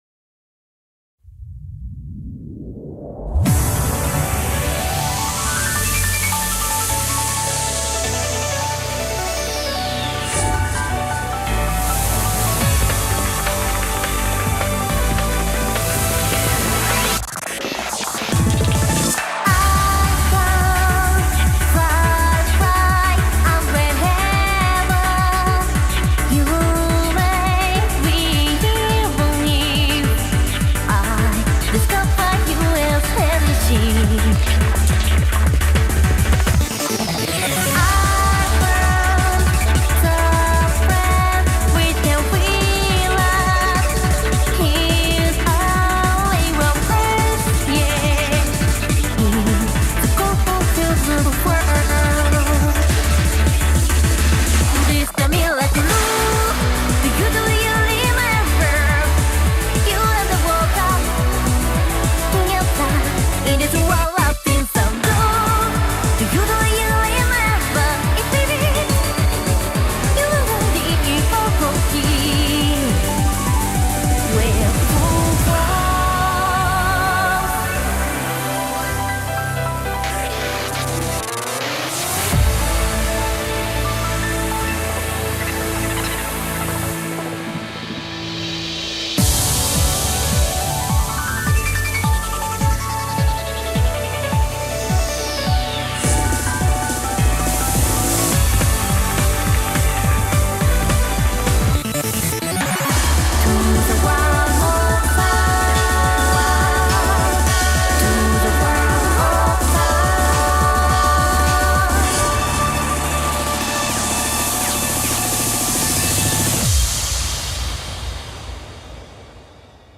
BPM105-210
Audio QualityCut From Video